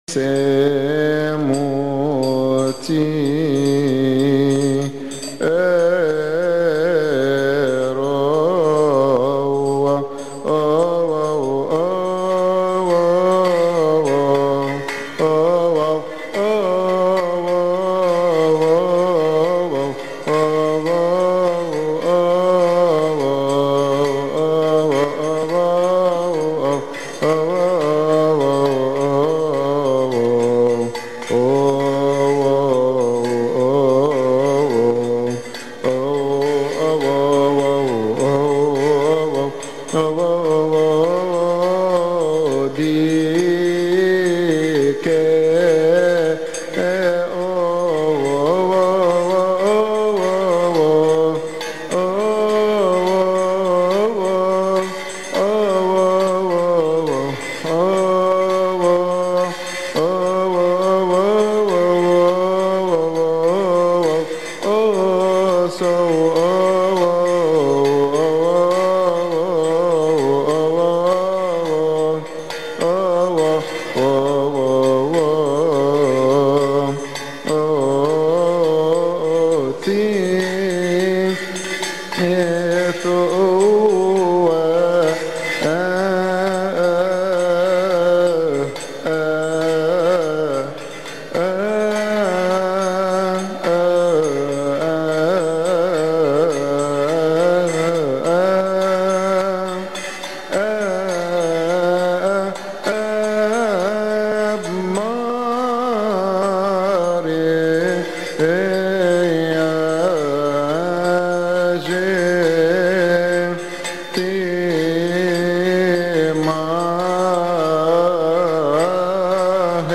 استماع وتحميل لحن لحن سيموتى من مناسبة keahk